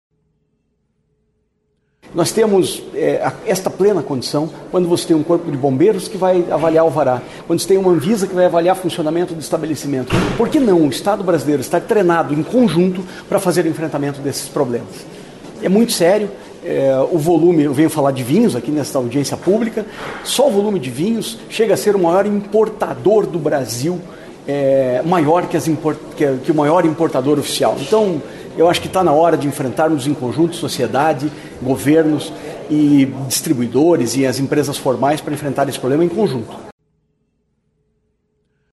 A Assembleia Legislativa do Paraná (ALEP) sediou, nesta quarta-feira (15), uma audiência pública para discutir os riscos do metanol à saúde e estratégias de combate à adulteração de bebidas alcoólicas.